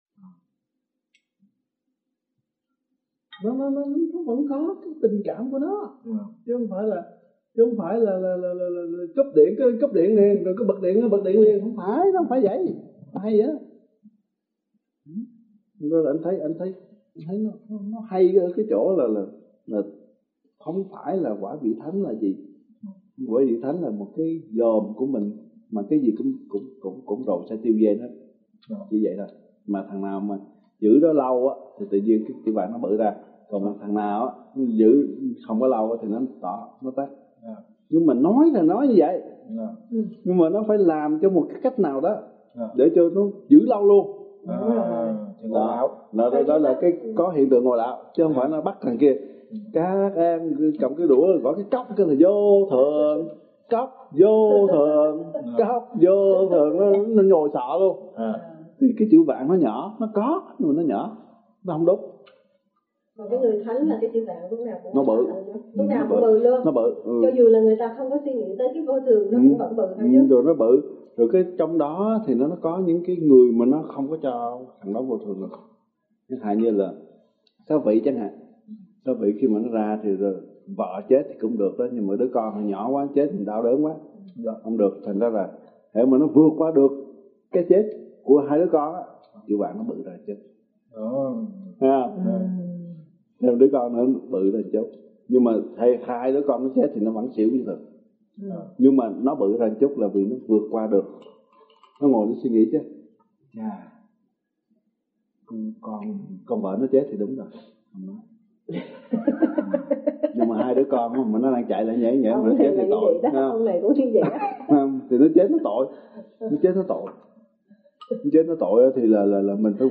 09:41 PM Mời bà con thưởng thức bài Thầy nói chuyện về Chữ Vạn và Thánh Quả: https